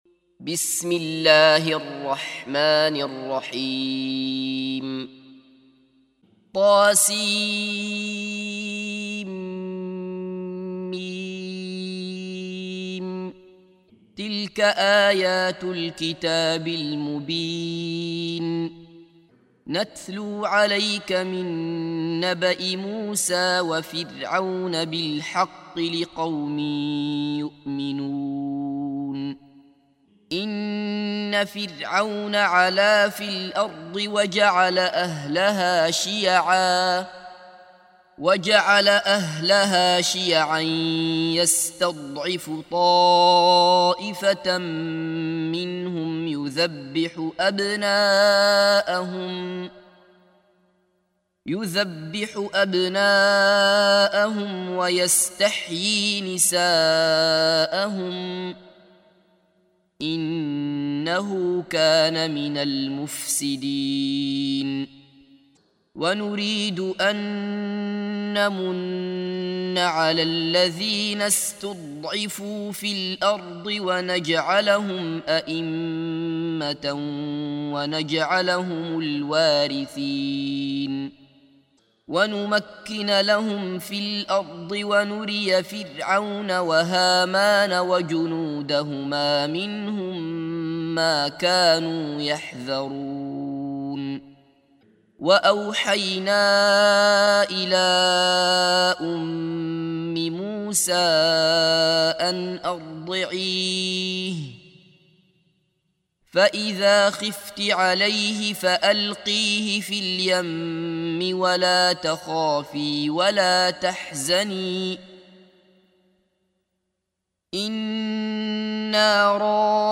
سُورَةُ القَصَصِ بصوت الشيخ عبدالله بصفر